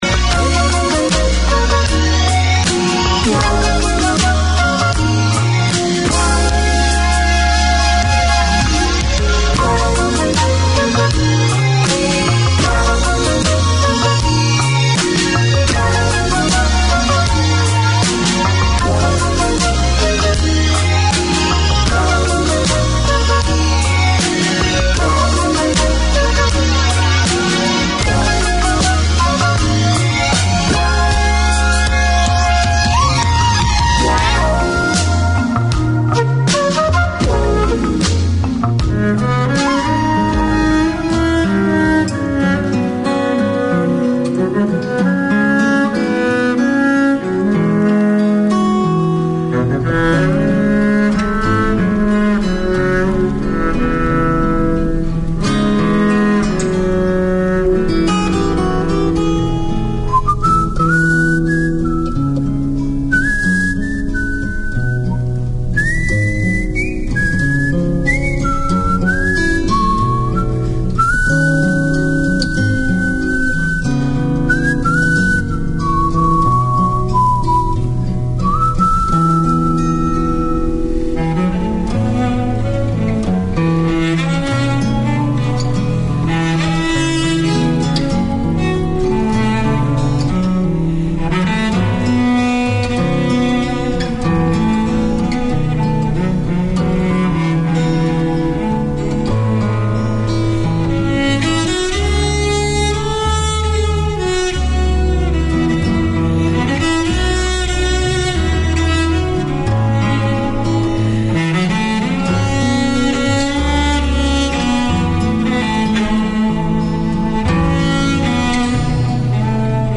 Each week Haamro Chautari offers the chance for the Nepalese community to gather round and share their culture, news of the local community as well as the latest from Nepal. The hosts also present a selection of new music and golden hits. Interviews and updates on community events in Auckland keep the connection with the Nepalese way of life.